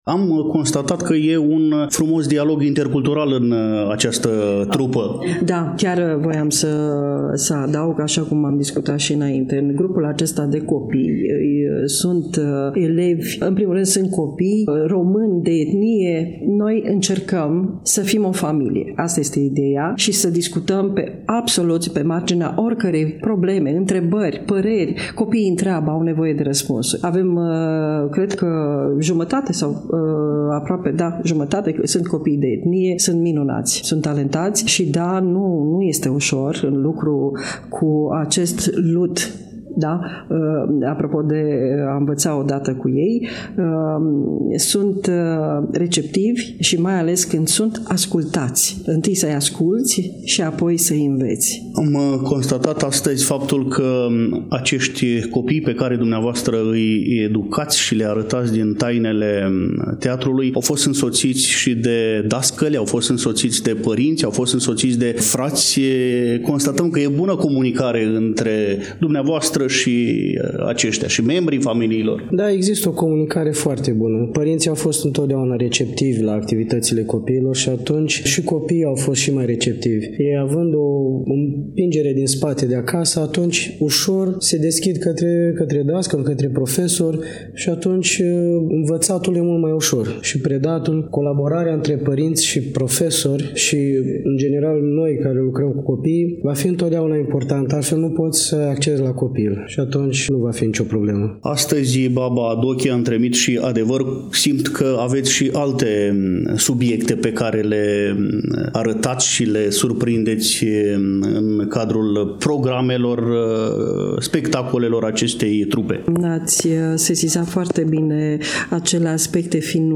În contextul subiectului Festivalului „Teodorenii”, Literatură și Tradiție la Iași, de la cei doi interlocutori ascultăm câteva mesaje.
2_ROIS-Dialog-6-10.mp3